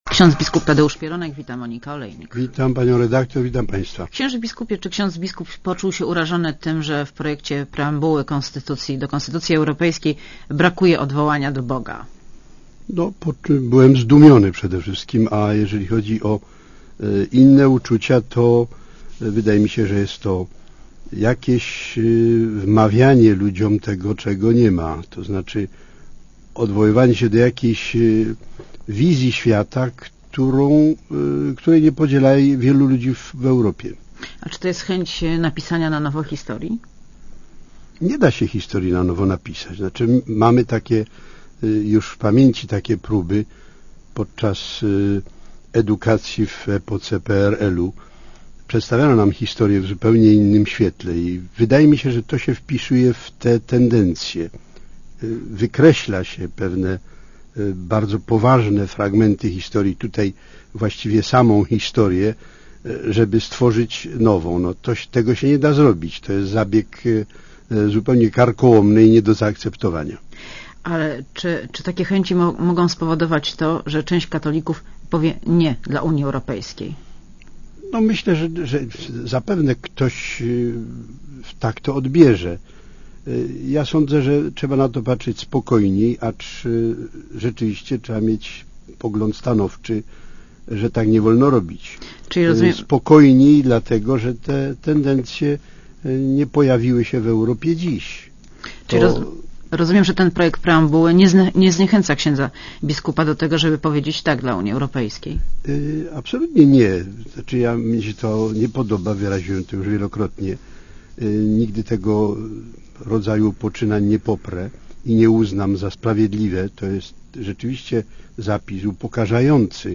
Monika Olejnik rozmawia z biskupem Tadeuszem Pieronkiem - rektorem Papieskiej Akademii Teologicznej
© (Archiwum) © (RadioZet) Posłuchaj wywiadu (2,7 MB) Księże biskupie, czy ksiądz biskup poczuł się urażony tym, że w projekcie preambuły do konstytucji europejskiej brakuje odwołania do Boga?